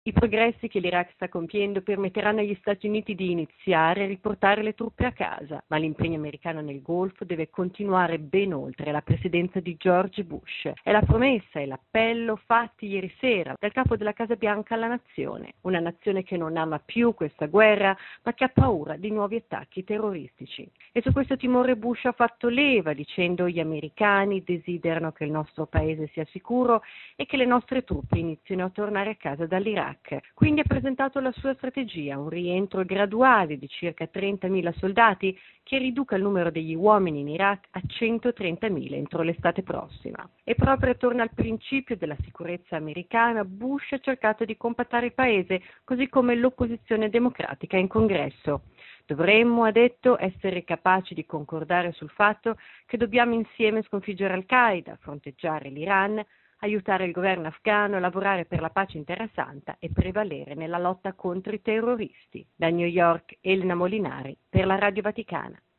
Il capo della Casa Bianca adotta, dunque, il piano, già anticipato nei giorni scorsi dal generale Petraeus, comandante delle truppe multinazionali in Iraq, ma non riceve ancora l’approvazione dell’opposizione democratica. Ce ne parla, da New York